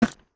Option Button Game Play.wav